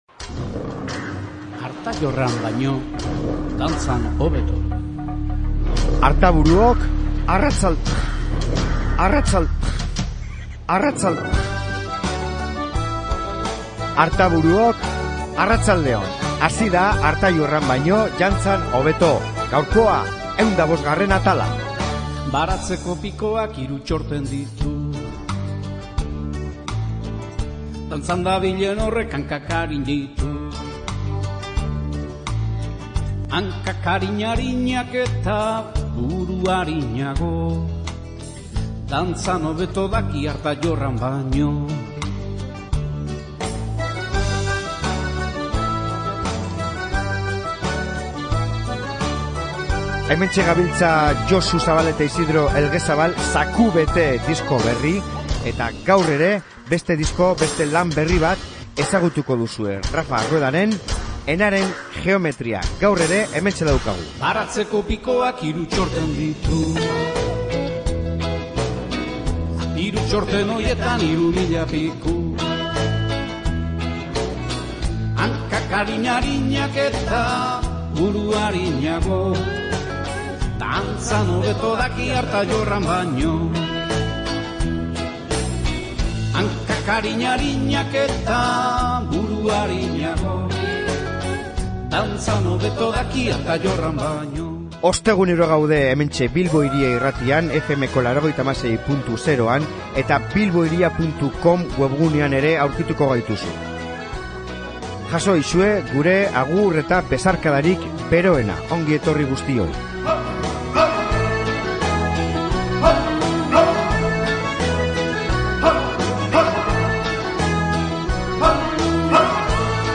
Elkarrizketa luze eta interesgarria edozein musikazalerentzat,bi zatitan eman beharrekoa!